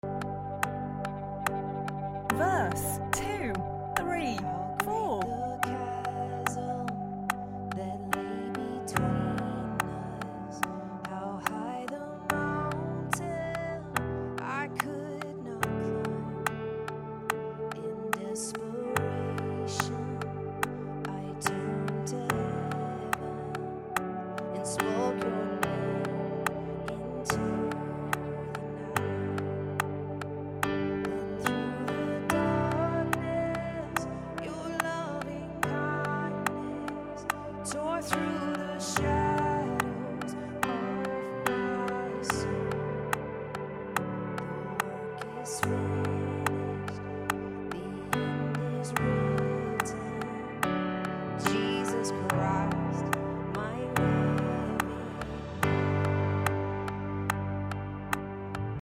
Cuando tocas piano y voz, sound effects free download